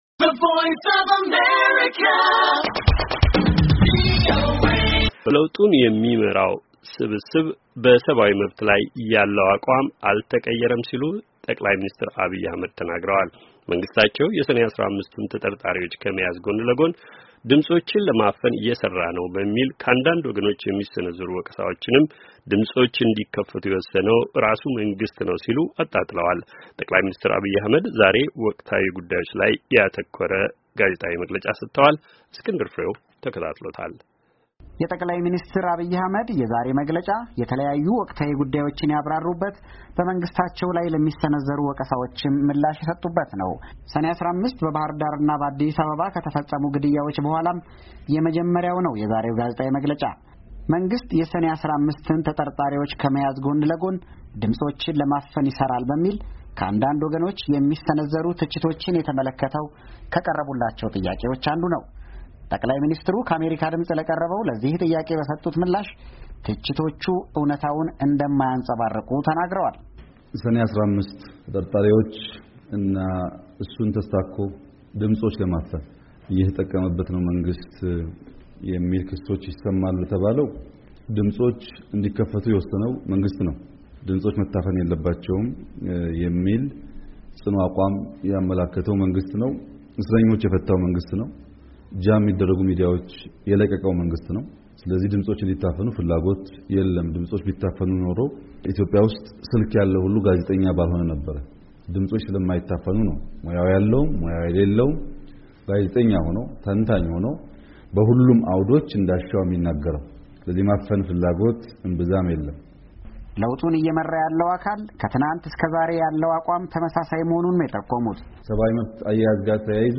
ጠ/ሚ ዐብይ በወቅታዊ ጉዳዮች ላይ መግለጫ ሰጡ